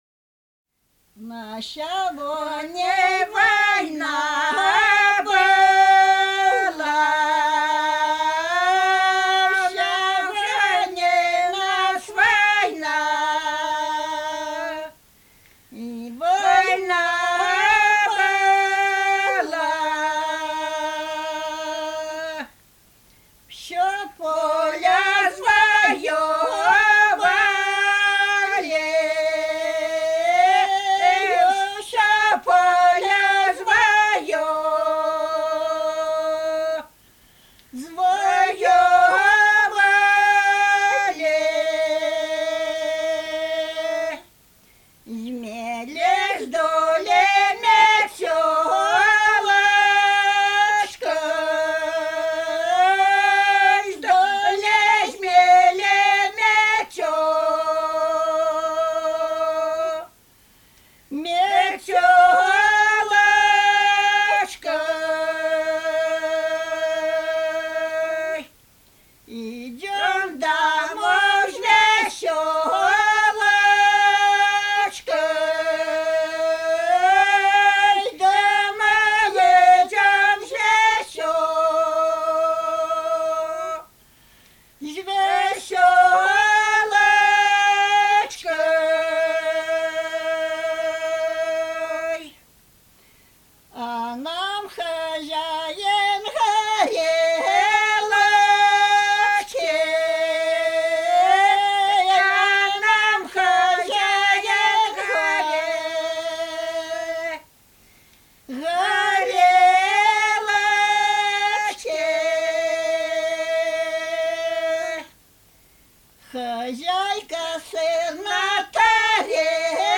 Протяженные тоны напева и регулярные глубокие цезуры способствовали установлению правильного дыхания, необходимого при тяжёлой работе.
Жнивная песня
из д. Молокоедово Невельского р-на Псковской обл.